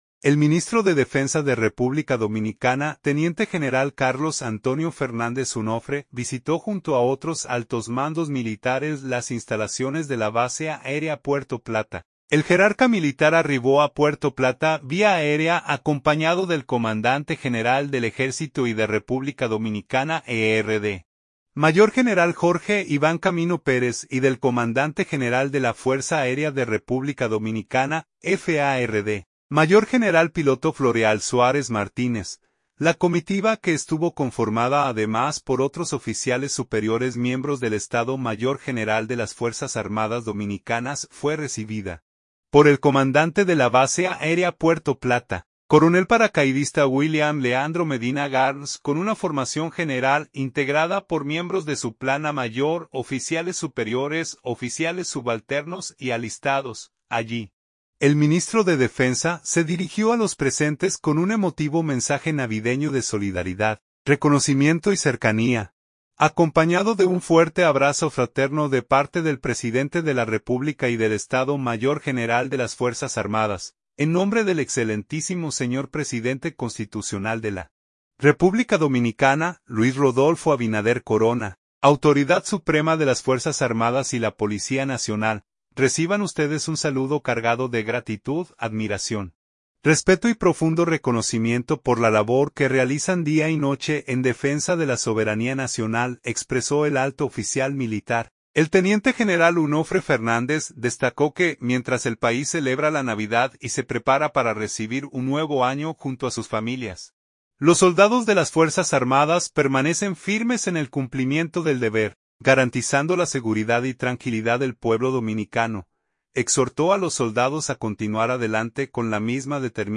Allí, el ministro de Defensa se dirigió a los presentes con un emotivo mensaje navideño de solidaridad, reconocimiento y cercanía, acompañado de un fuerte abrazo fraterno de parte del presidente de la República y del Estado Mayor General de las Fuerzas Armadas.